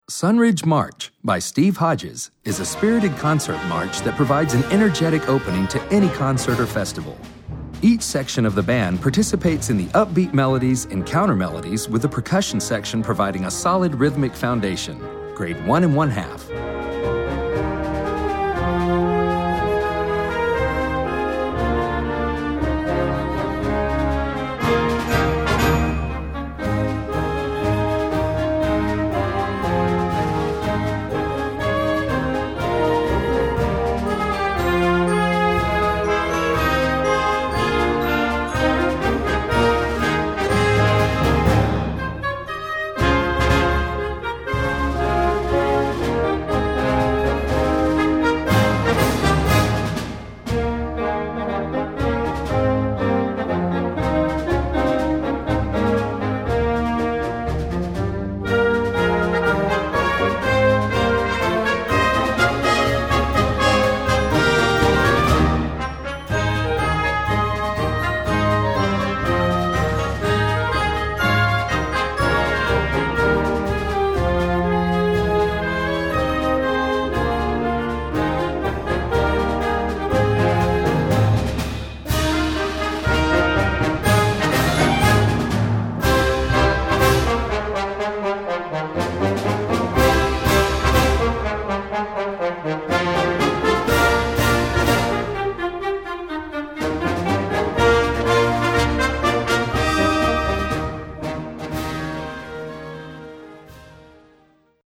Gattung: Werk für Jugendblasorchester
2:30 Minuten Besetzung: Blasorchester PDF